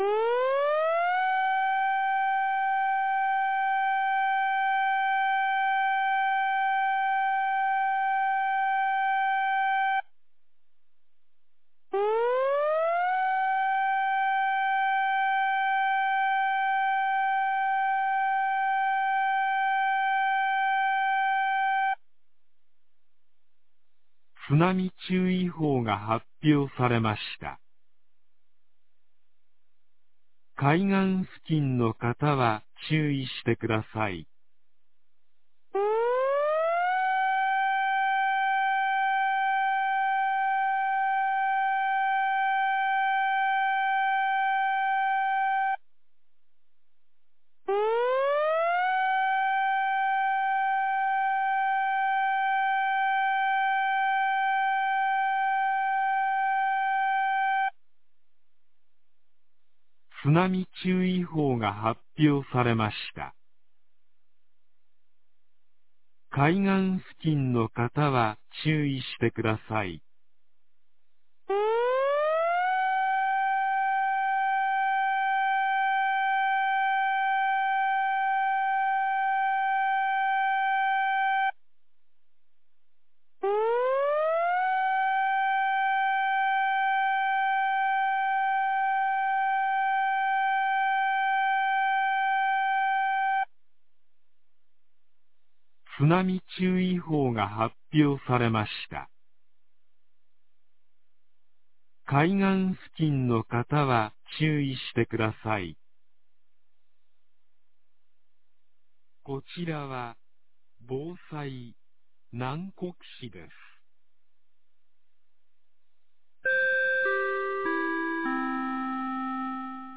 放送音声
2024年08月08日 16時46分に、南国市より放送がありました。